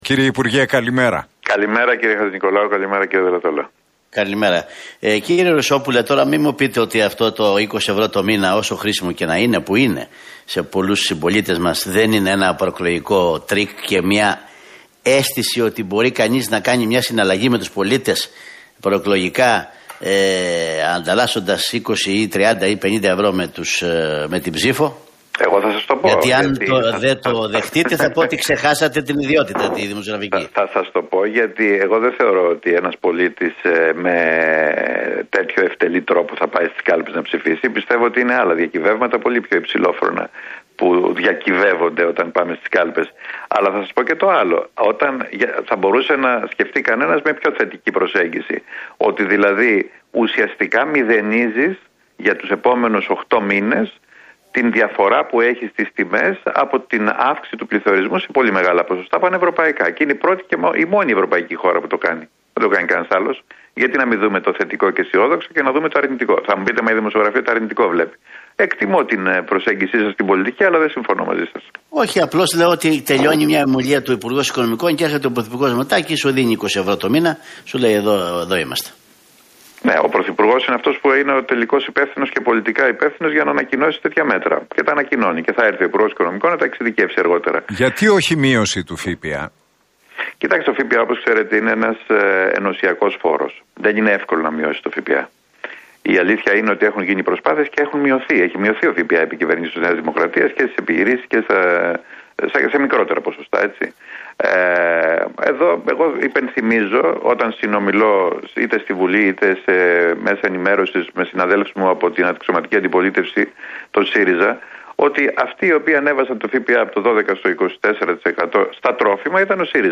σε συνέντευξή του στον Realfm 97,8